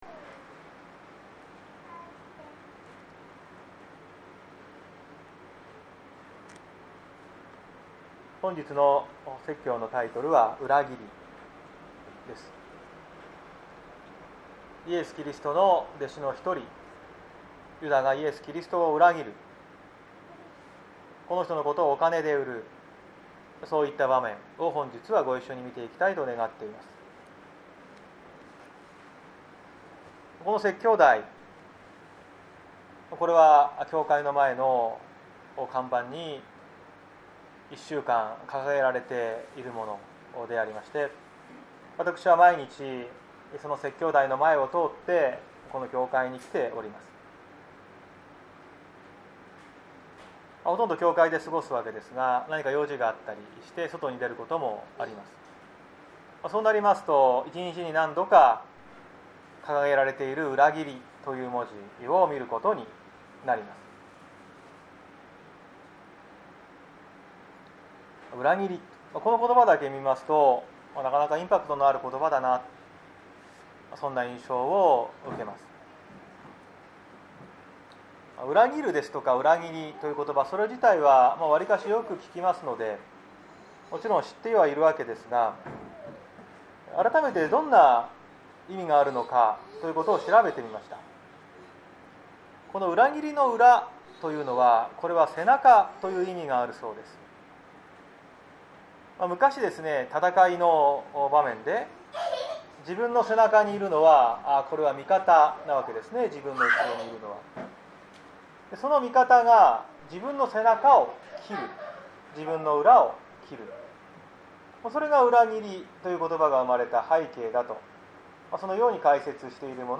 2021年08月08日朝の礼拝「裏切り」綱島教会
説教アーカイブ。